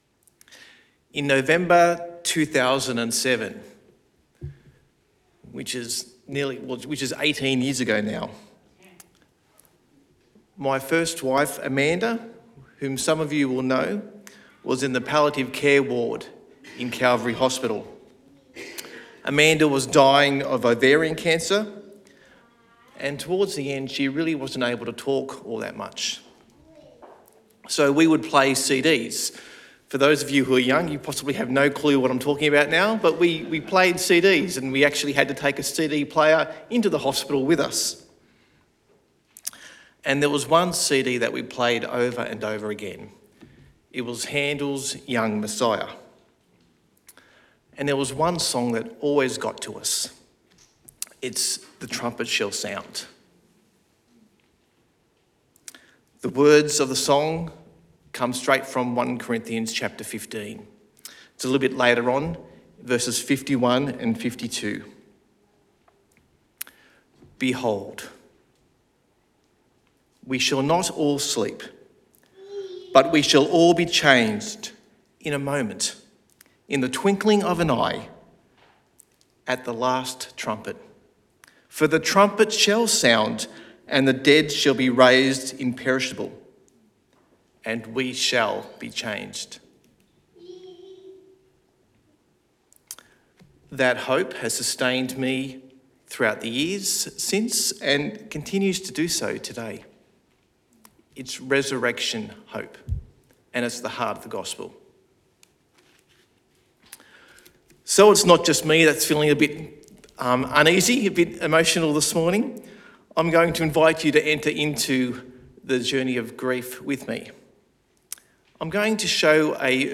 Sermon Podcasts How the Gospel changes everything